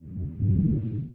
foot_1.wav